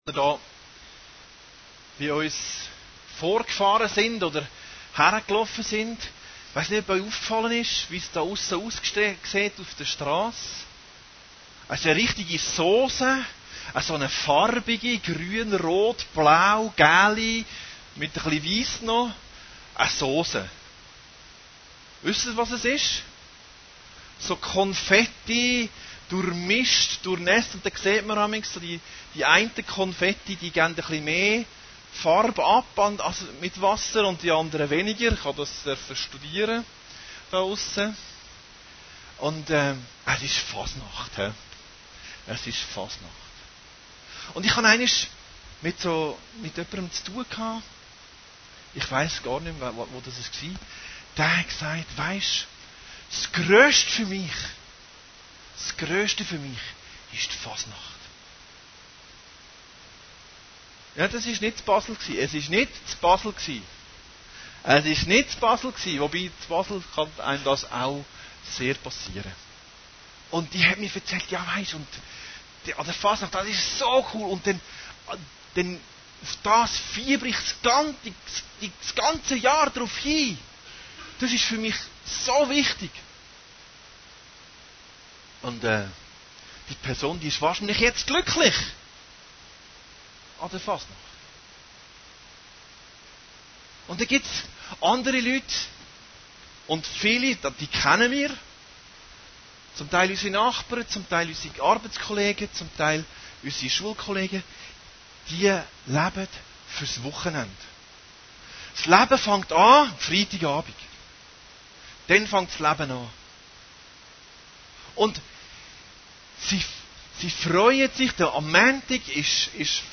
Predigten Heilsarmee Aargau Süd – Die Stimme Gottes hören - im Alltag